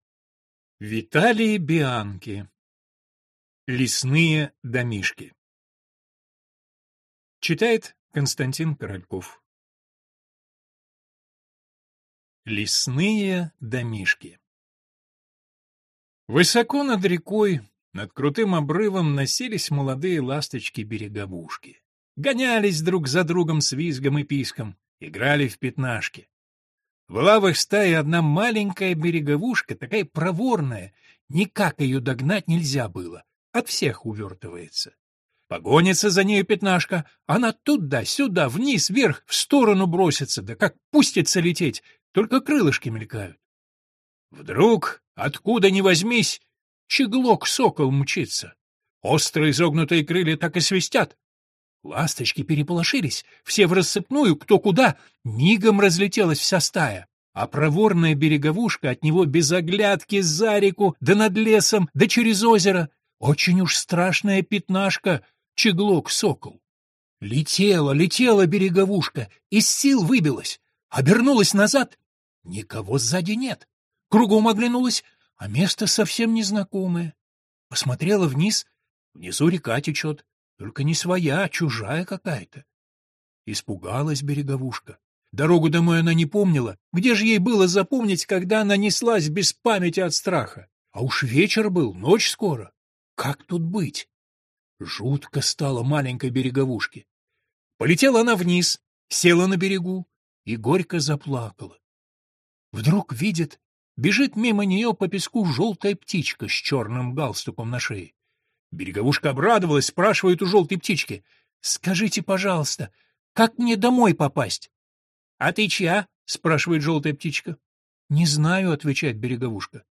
Аудиокнига Лесные домишки | Библиотека аудиокниг